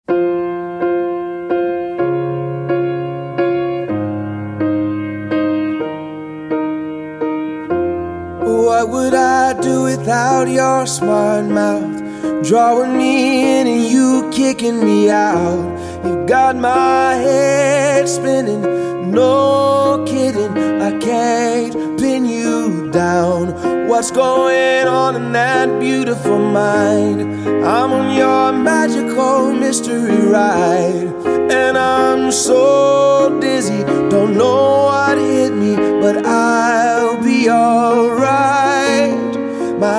rnb
романтические
баллады